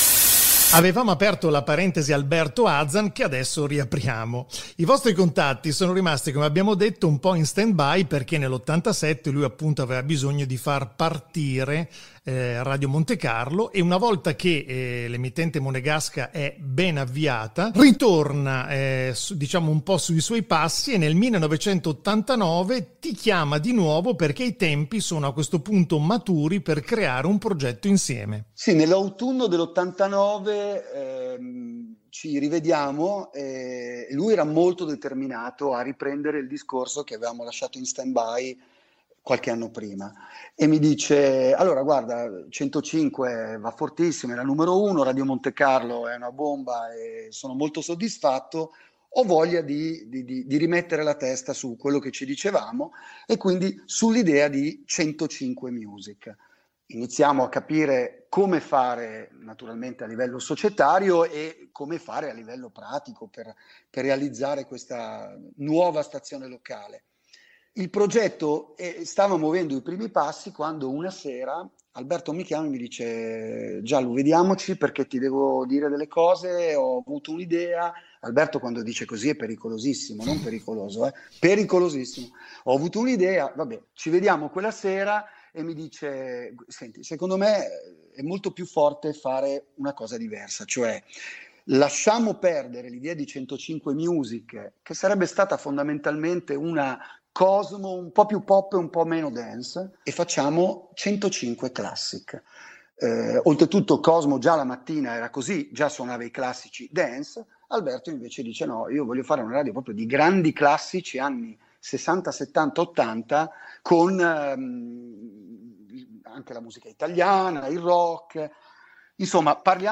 spezzone di intervista